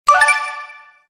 Victory-sound-effect.mp3